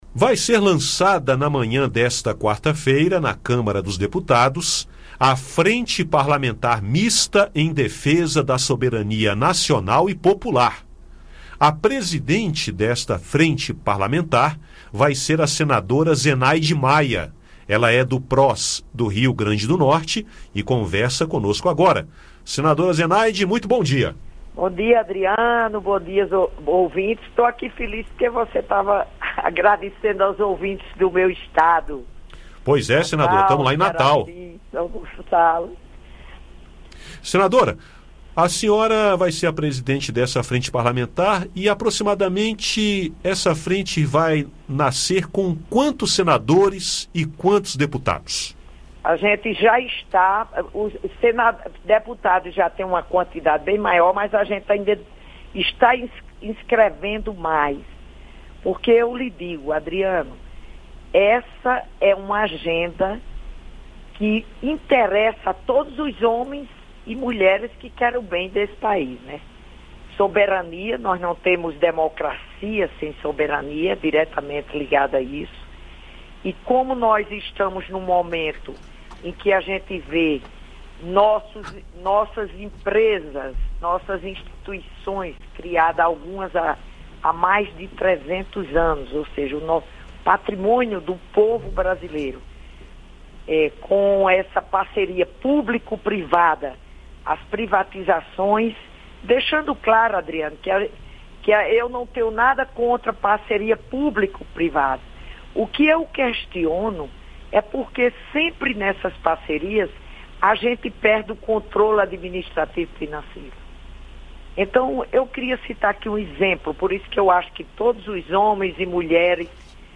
Nesta terça (3), conversamos com a senadora, que faz críticas às privatizações do governo. Ouça o áudio com a entrevista.